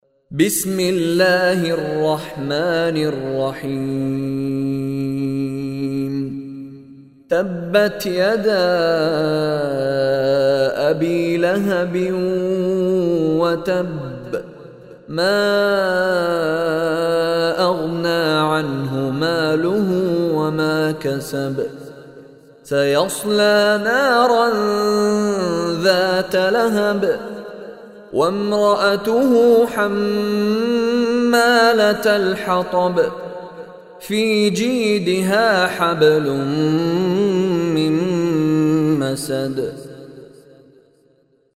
Surah Masad or Surah Lahab listen online and download beautiful recitation / tilawat in the voice of Sheikh Mishary Rashid Alafasy.